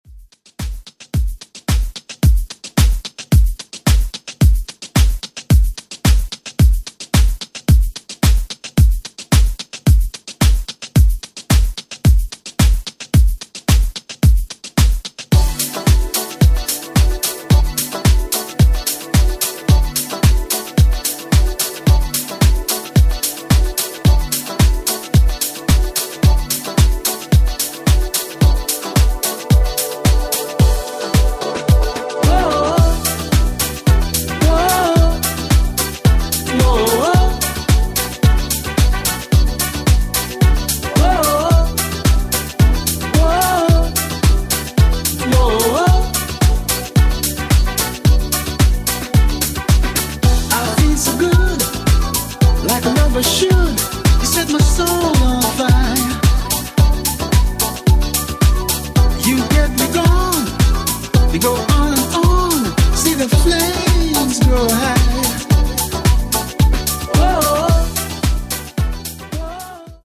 NU-Disco Remix